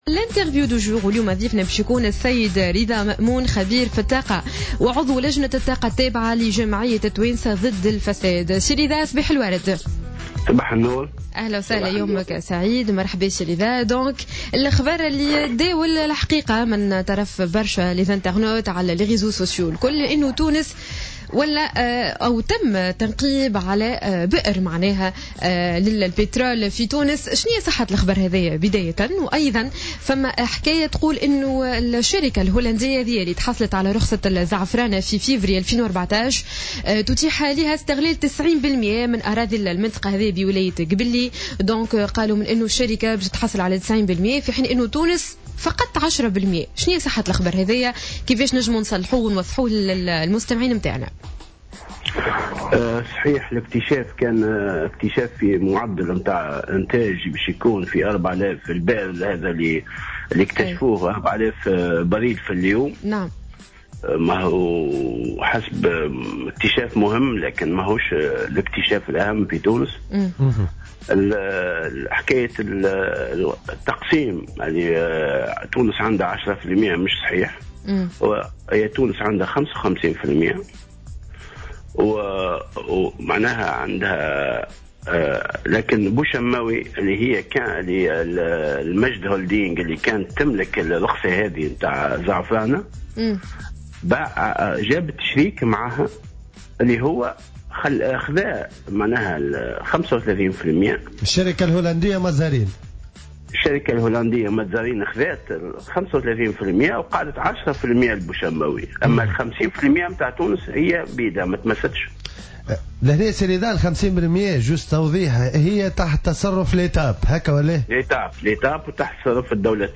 في مداخلة له على جوهرة "اف ام" اليوم الإثنين